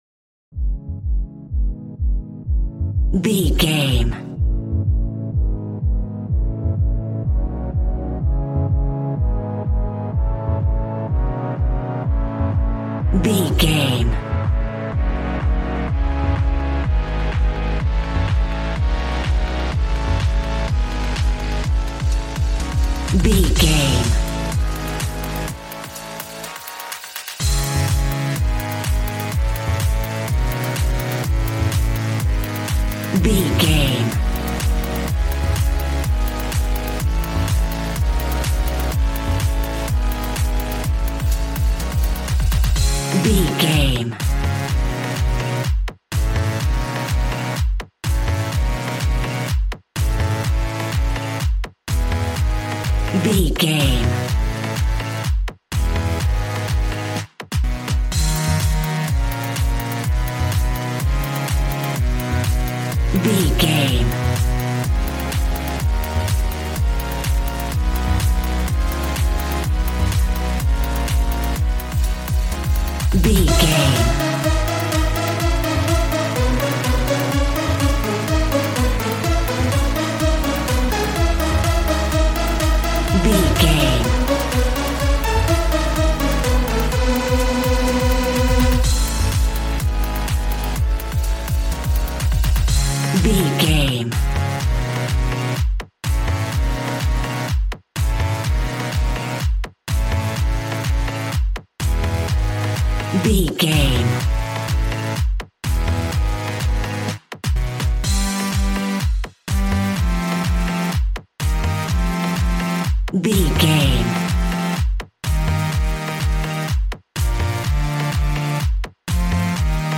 Aeolian/Minor
A♭
Fast
groovy
energetic
synthesiser
drums